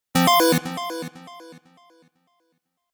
Scifi 15.mp3